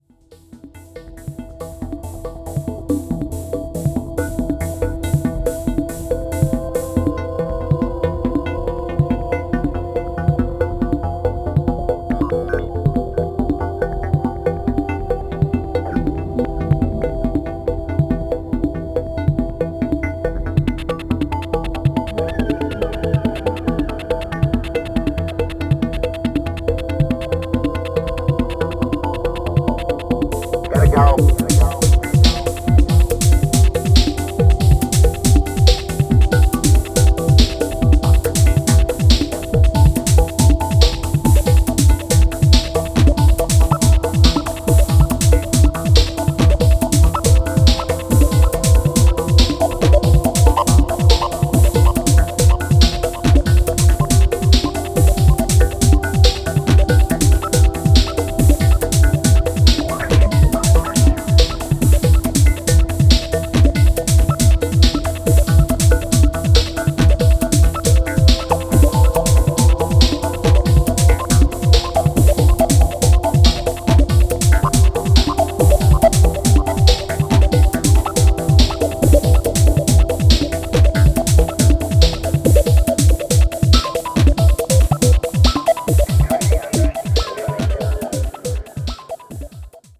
イルカの鳴き声も交えて浮遊するアンビエントな上物が醸すニューエイジ感と硬質なエレクトロビートのミスマッチ感が面白い
ポスト・パンキッシュ・レイヴ！